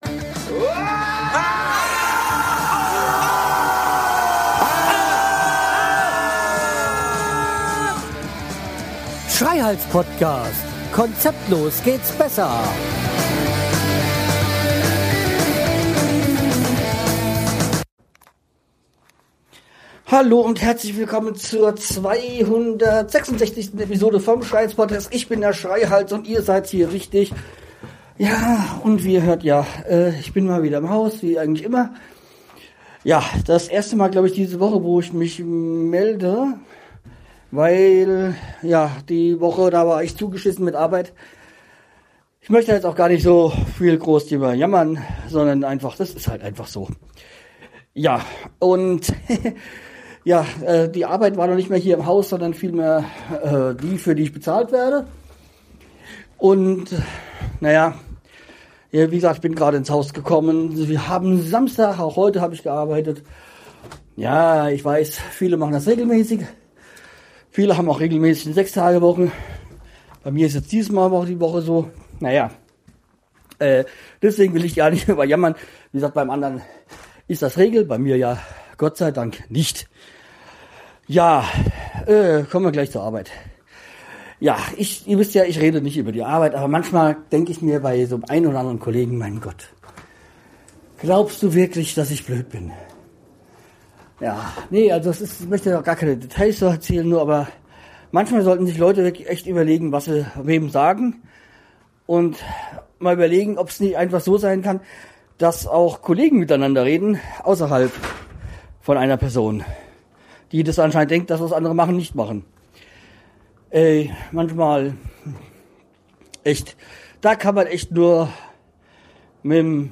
Ich bin nun wieder mit dem Handy Recorder unterwegs.